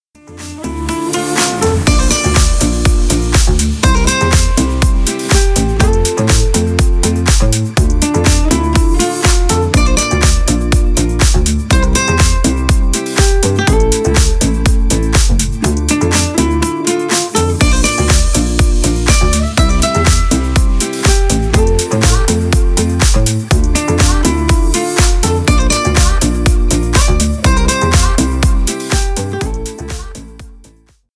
Поп Музыка
клубные # без слов